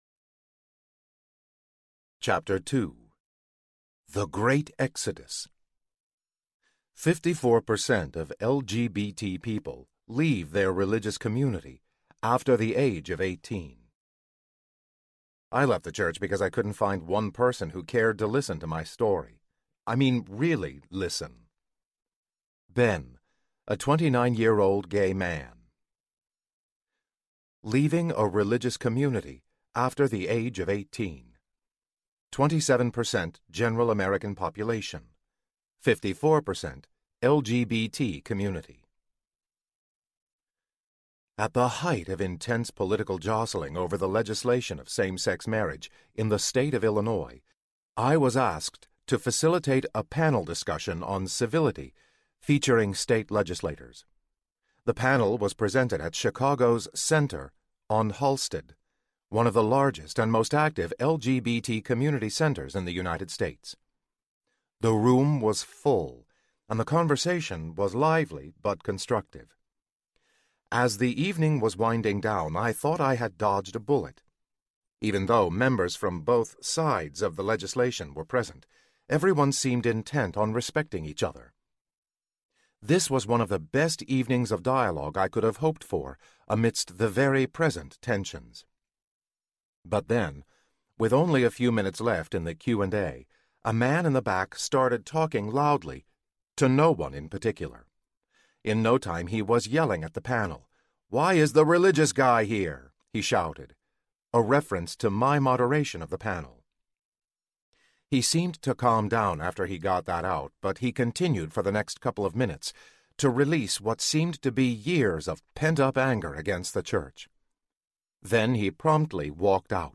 Us versus Us Audiobook
Narrator
5.6 Hrs. – Unabridged